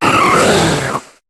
Cri d'Oniglali dans Pokémon HOME.